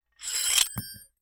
Metal_65.wav